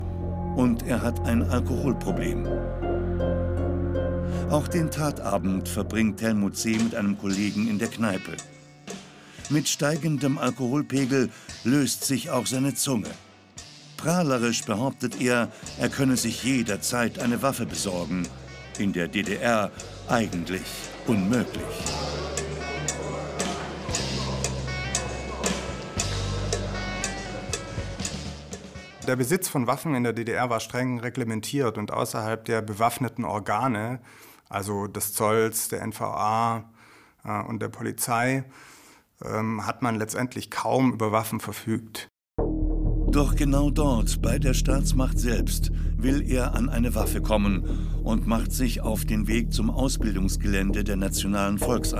Ich suche die englischsprachige Rockband vermutlich späte 1970iger oder um 1980 deren Song in einer ZDF Doku über Kriminalfälle in der DDR als kurzer Musikschnipsel zur Untermalung verwendet wurde.
Anhänge Welche Rockband Band.mp3 Welche Rockband Band.mp3 1,1 MB